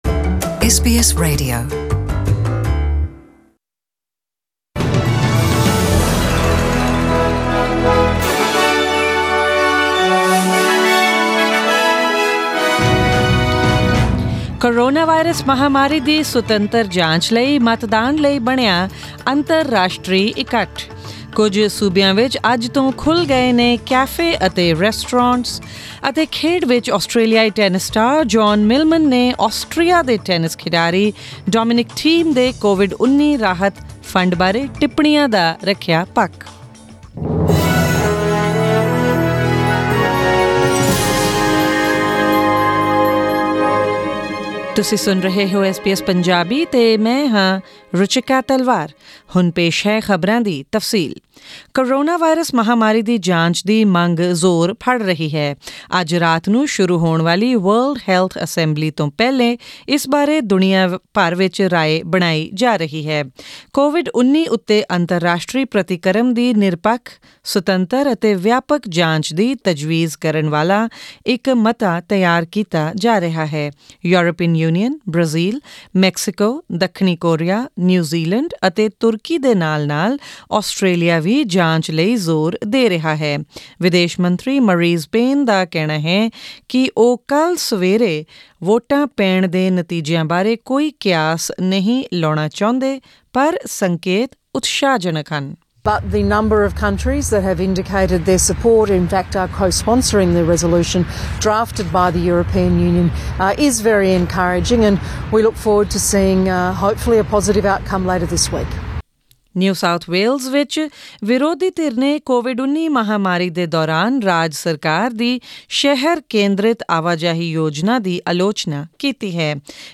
Presenting the major national and international news stories of today; including updates on sports, currency exchange rates and the weather forecast for tomorrow.